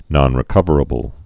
(nŏnrĭ-kŭvər-ə-bəl)